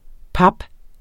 Udtale [ ˈpɑb- ]